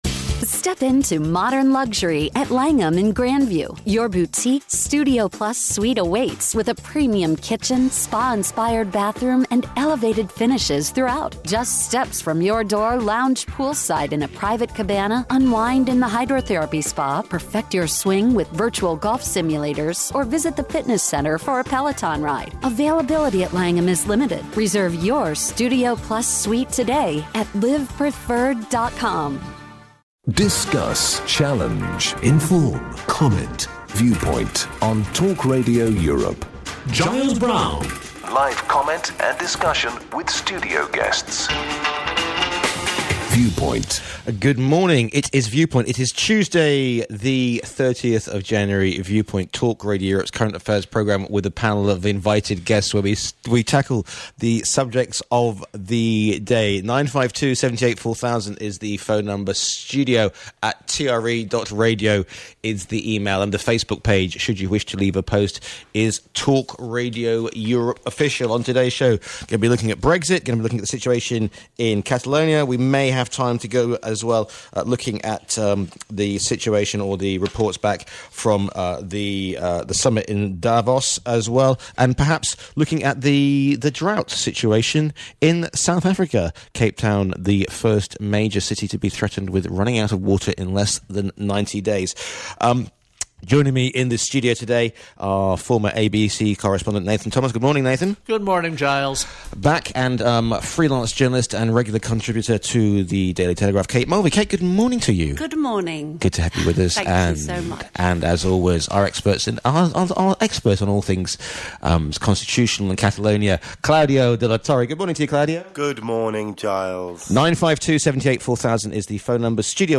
The panel discuss the major and breaking news stories of the week. The show is uncensored, often controversial, and full of heated debate.